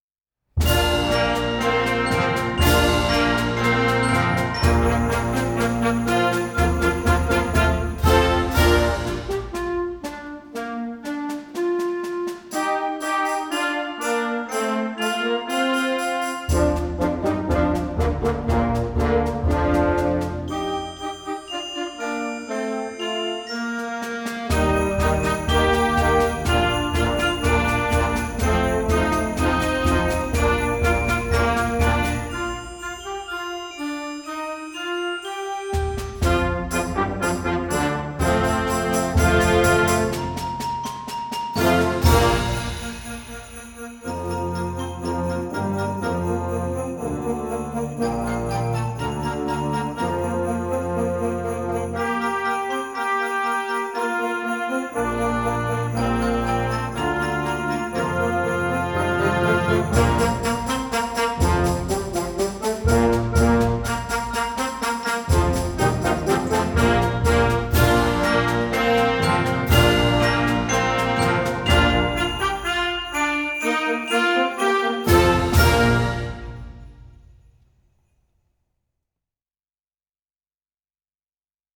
Voicing: Concert Band
Composer: Traditional